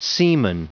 Prononciation du mot seaman en anglais (fichier audio)
Prononciation du mot : seaman